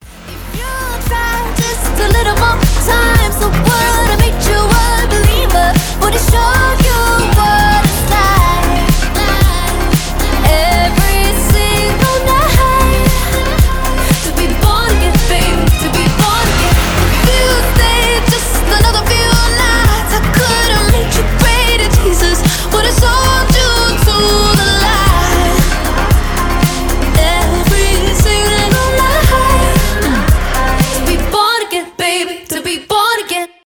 electropop
диско